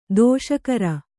♪ dōṣakara